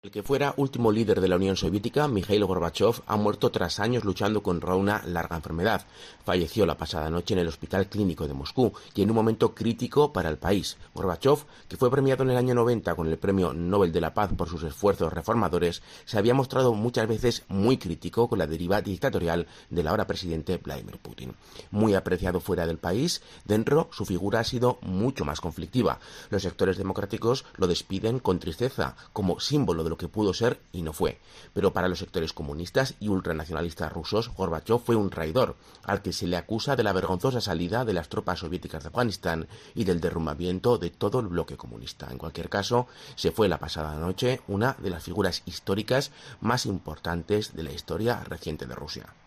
Gorbachov confirmaba la defunción de la Unión de Repúblicas Socialistas Soviéticas en un histórico discurso el 25 de diciembre de 1991